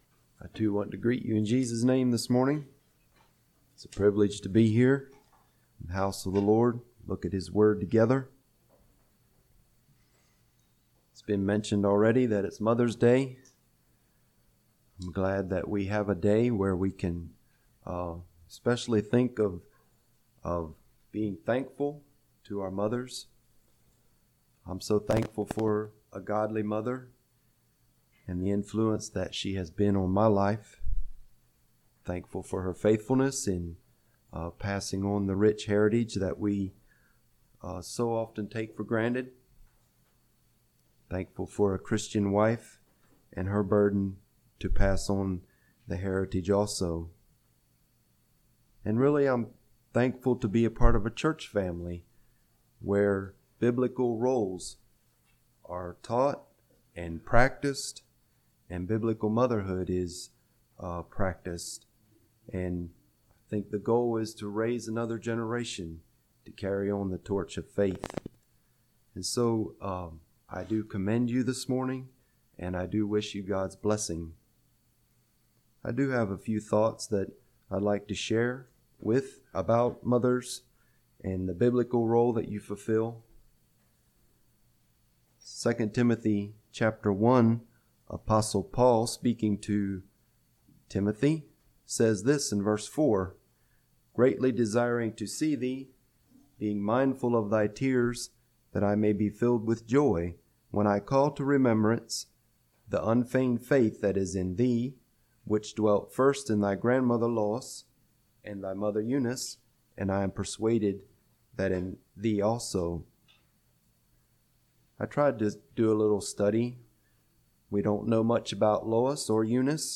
Sermons
Ridge View | Tent Meetings 2025